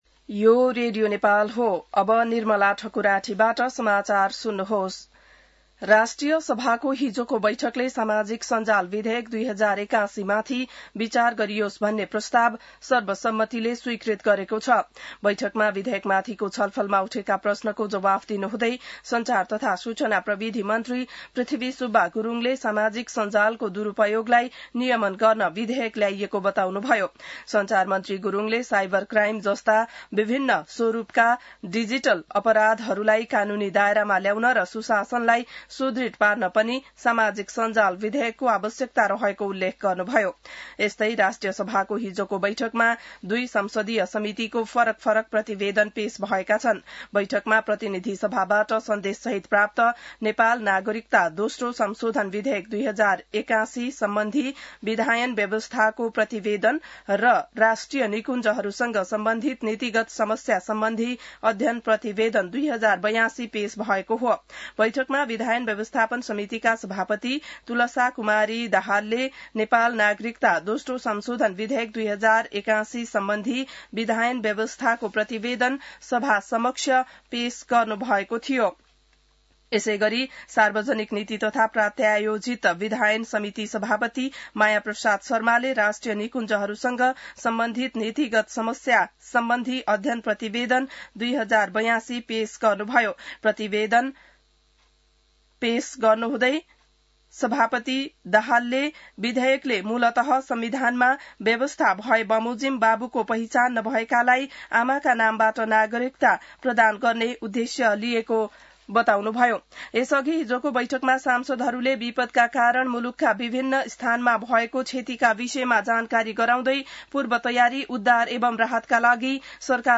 बिहान ६ बजेको नेपाली समाचार : ४ भदौ , २०८२